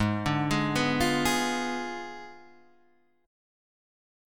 G#dim7 chord